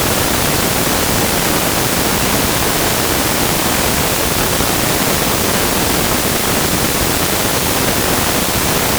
There is more low-frequency noise than high-frequency noise, so our noise is "pink."
We design a circuit to generate noise, implement the circuit, then record that noise using a sound card. All of the recordings here are CD-quality monaural: 16-bit samples at 44.1 KHz.